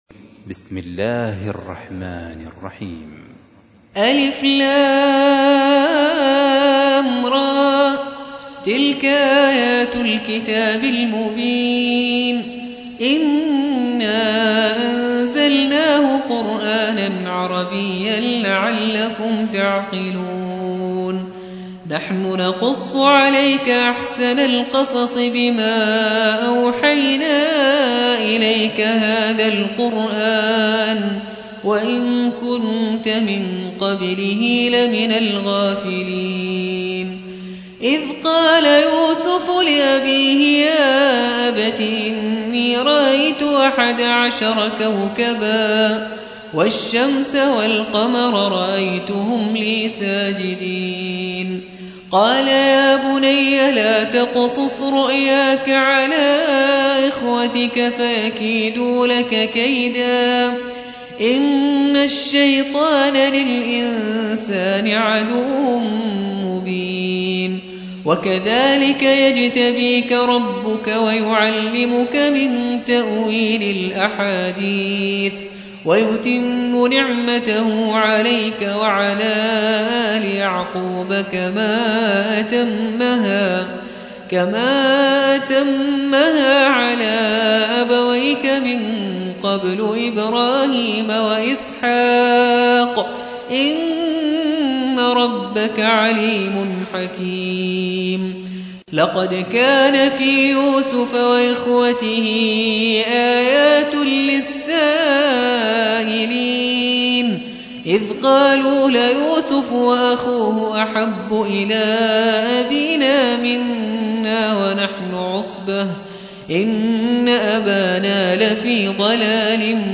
12. سورة يوسف / القارئ